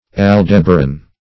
Aldebaran \Al*deb"a*ran\ ([a^]l*d[e^]b"[.a]*r[a^]n), prop. n.